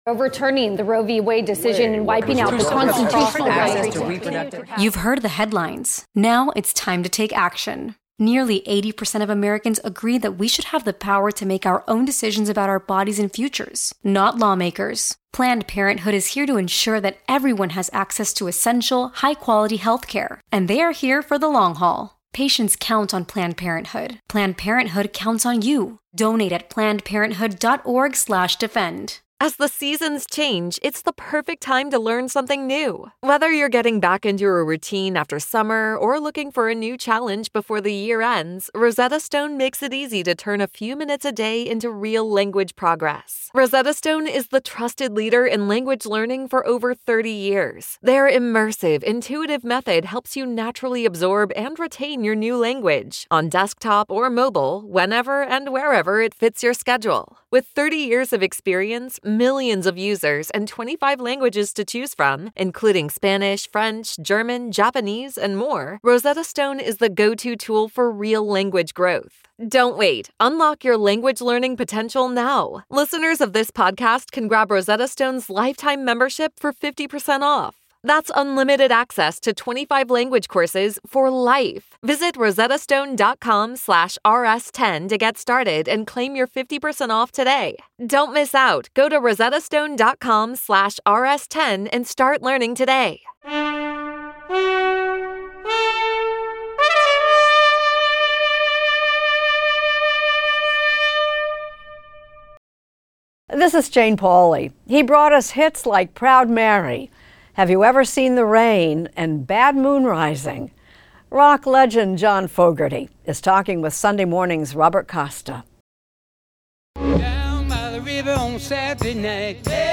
Extended Interview: John Fogerty